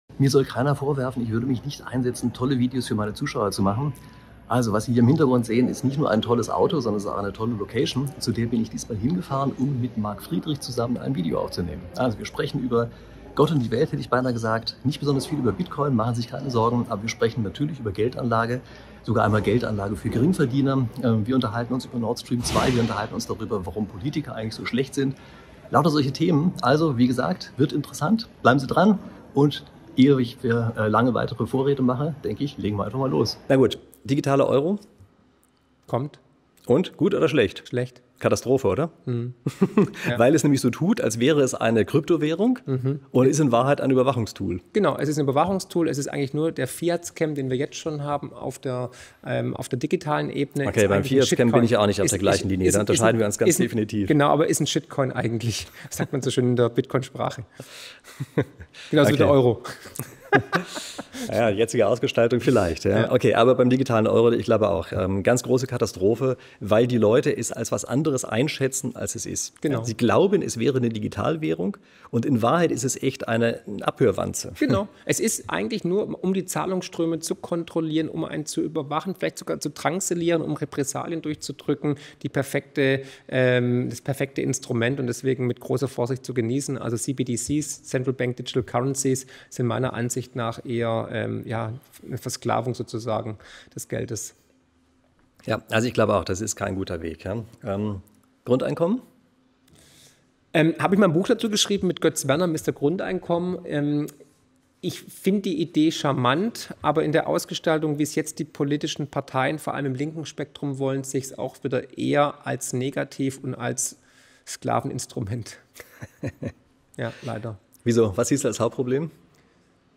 Marc Friedrich und Prof. Rieck unterhalten sich in einem Speed-Interview über Anregungen unserer Zuschauer.